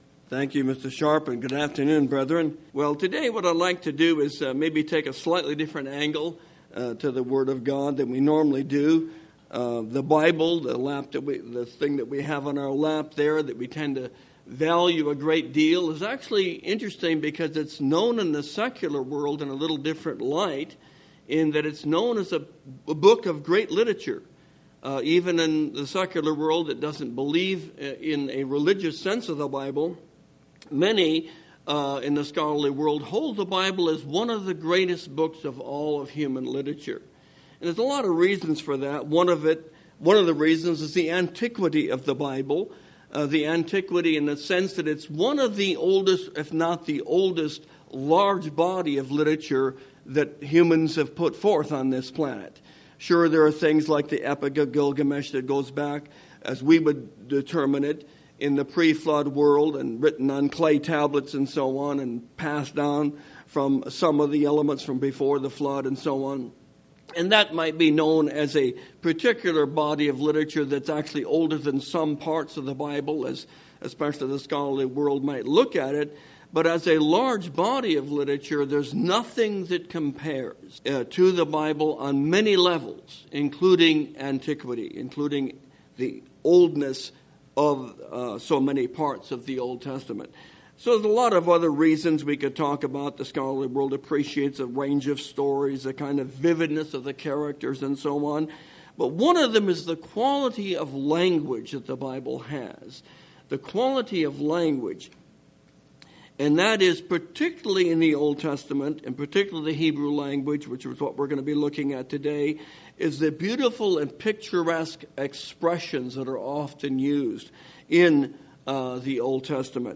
This sermon explores the colorful uses of words in the Bible that help describe and inspire situations and places.